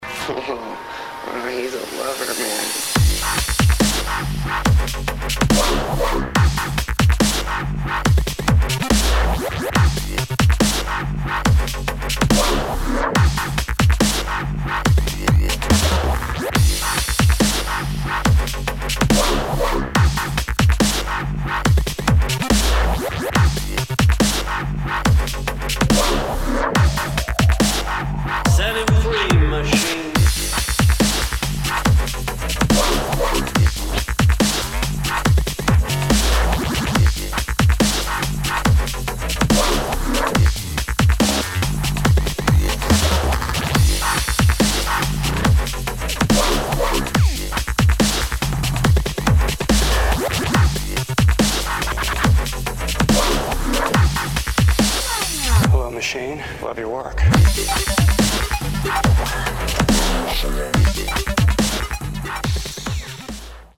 [ DUBSTEP / TRAP / GRIME ]